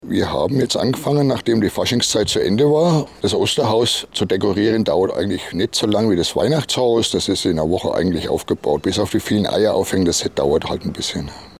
Interview: Das Osterhaus in Mellrichstadt - PRIMATON